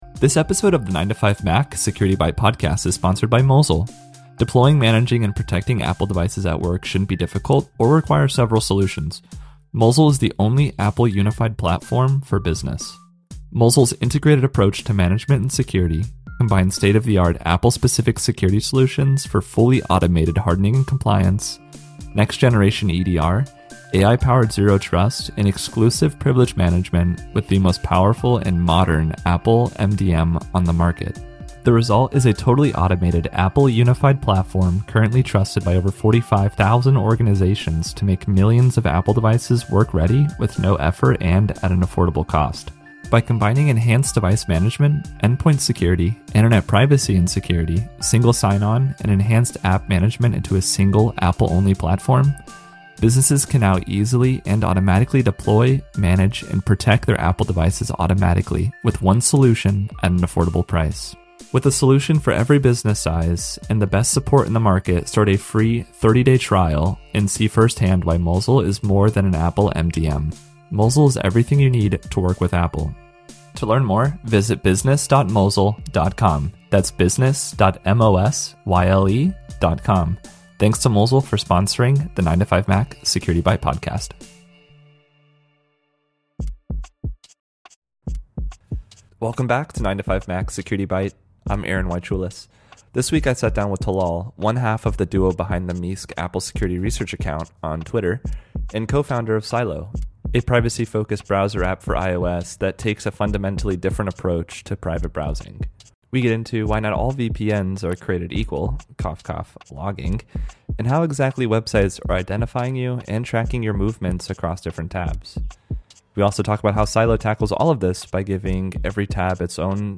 هذه هي محادثتي